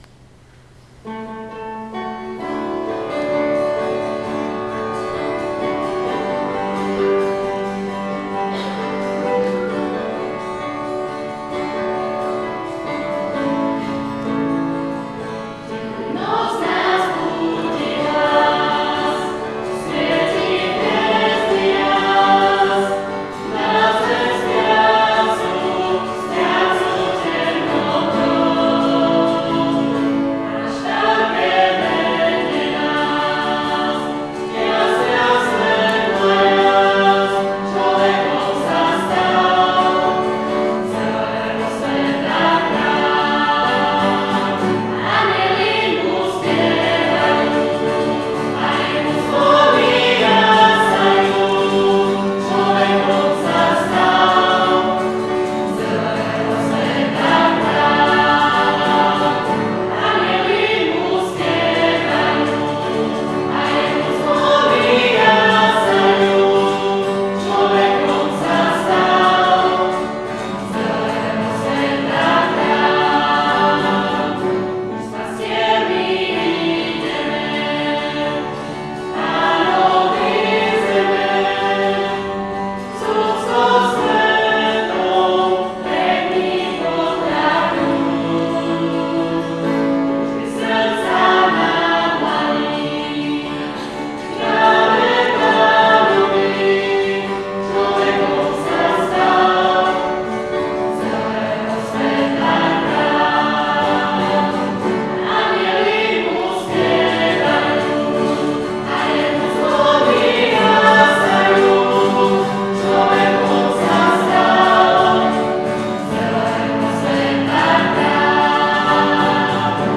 Trojkráľový koncert.
Atmosféra bola príjemná.
Nahrávky zvuku boli robené bežným diktafónom, preto ich kvalita nie je veľmi dobrá.
Mládežnícky zmiešaný zbor Lúka – Hrádok
Mládežnícky-zmiešaný-zbor-Lúka-Hrádok.wav